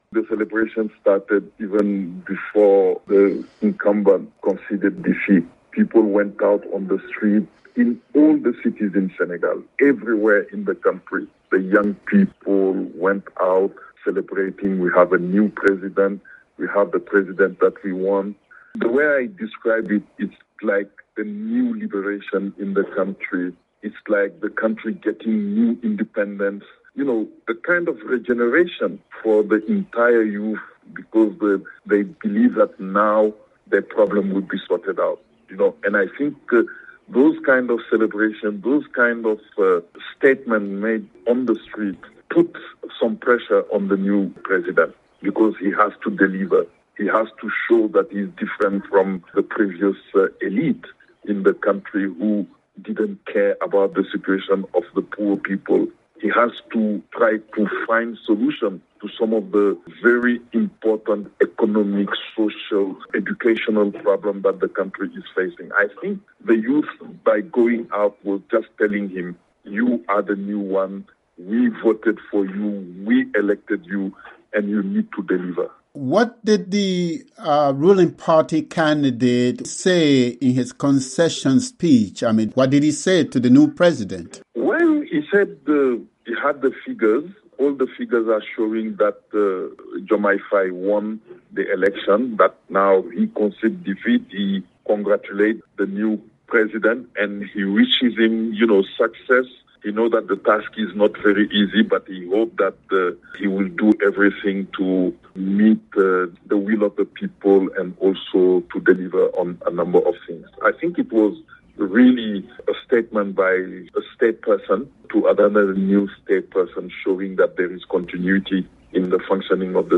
Senegalese political analyst